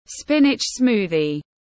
Sinh tố rau chân vịt tiếng anh gọi là spinach smoothie, phiên âm tiếng anh đọc là /ˈspɪn.ɪtʃ ˈsmuː.ði/